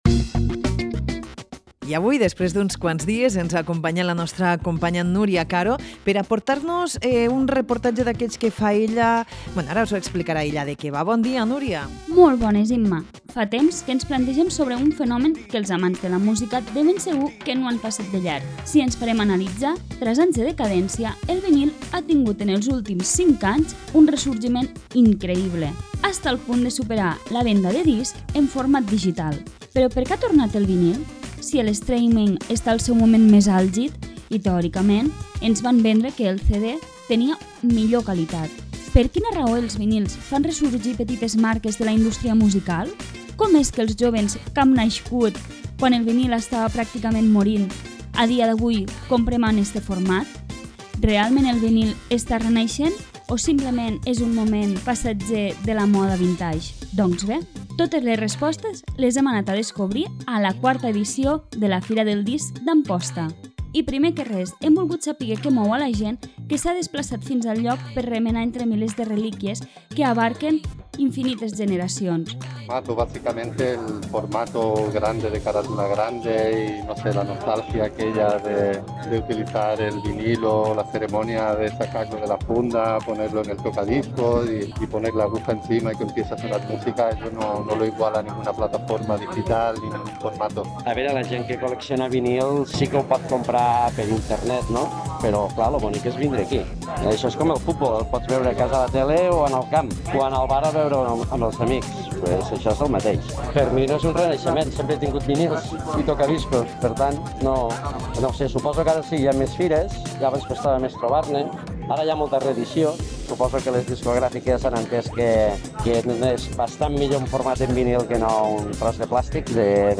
Aquesta edició de la Fira del disc d’Amposta ha tingut una visita molt especial.
ens porta un reportatge d’allò més interessant.